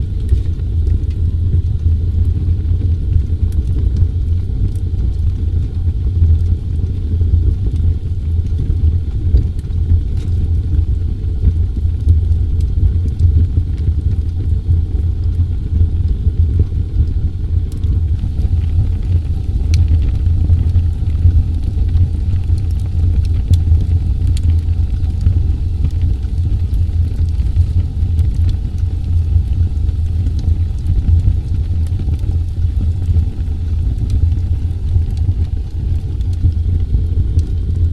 ambientFire3.ogg